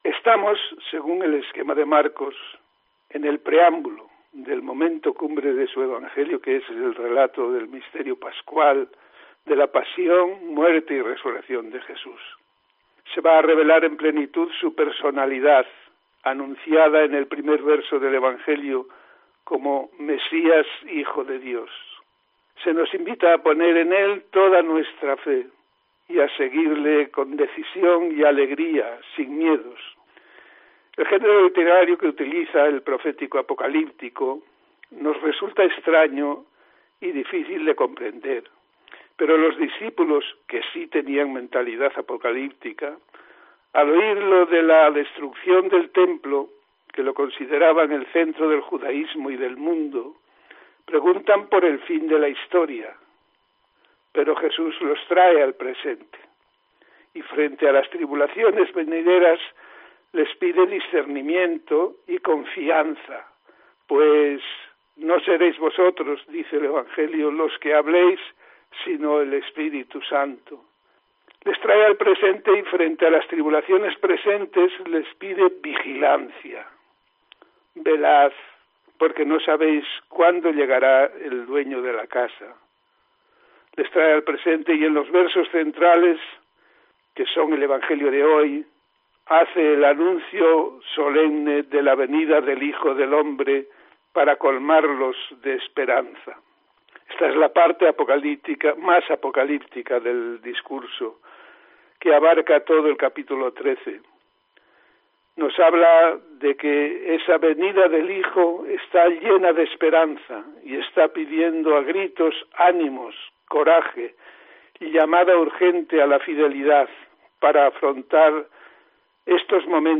Comentario del Evangelio de este domingo, 14 de noviembre de 2021